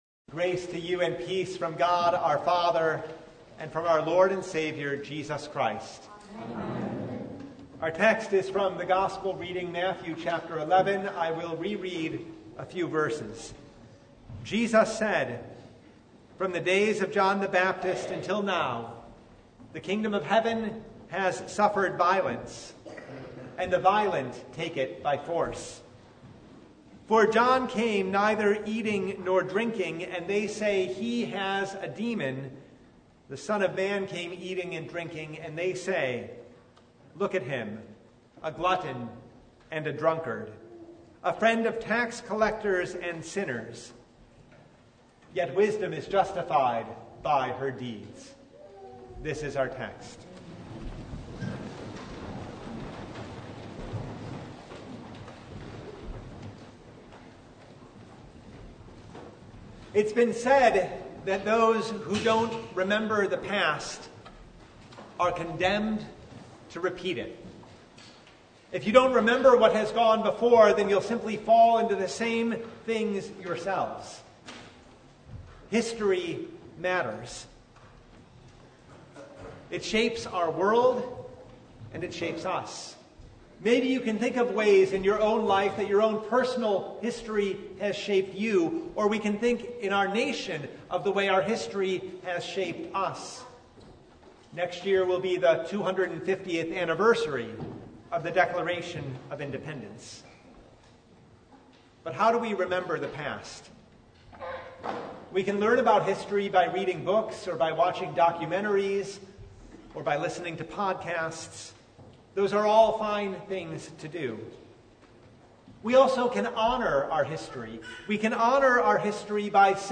Matthew 11:12–19 Service Type: The Festival of the Reformation What was the Reformation about?